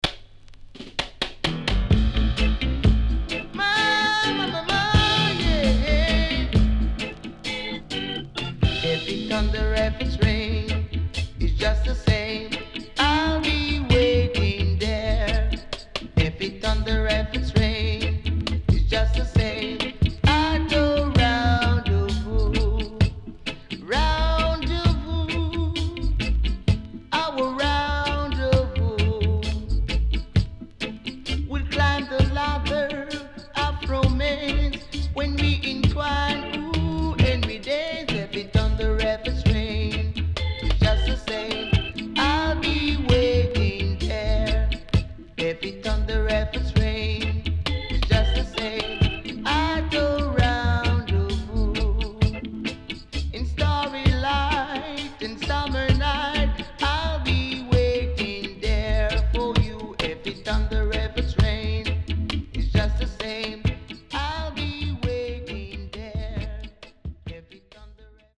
Killer Roots Vocal